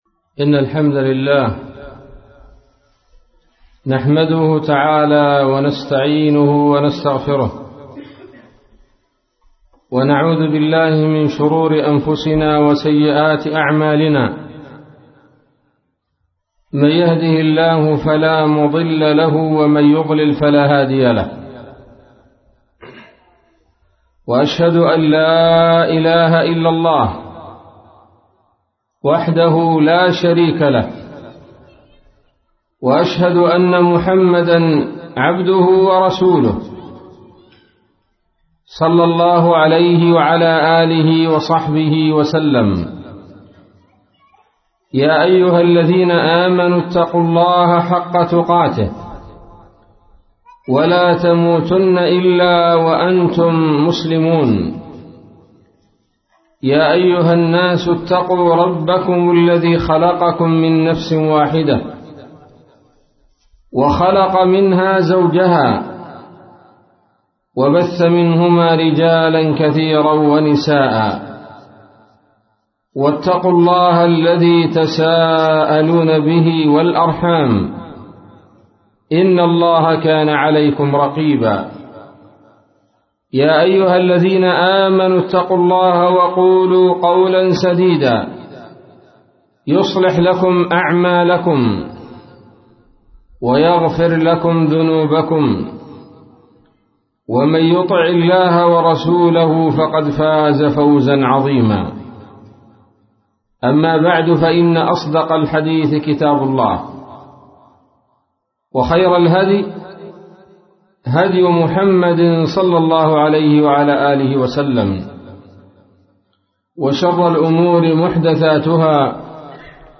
محاضرة بعنوان